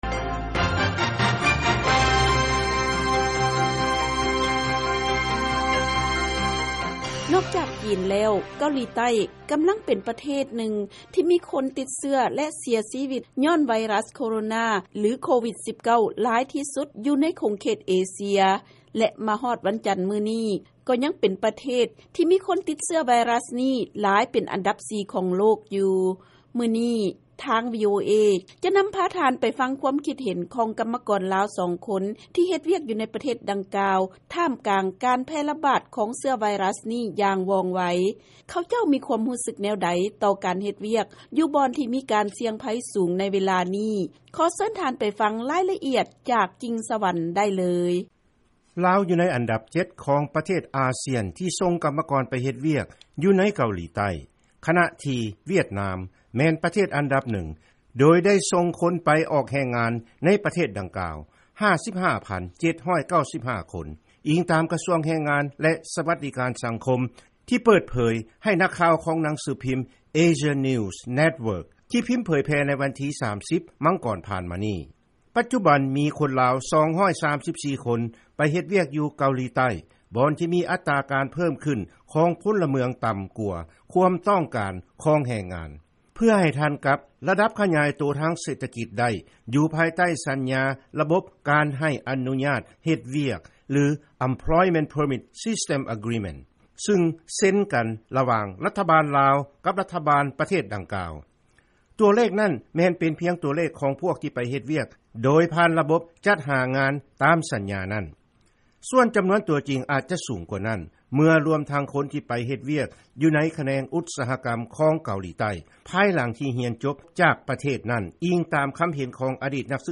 ເຊີນຟັງລາຍງານ ແຮງງານລາວໃນເກົາຫຼີໃຕ້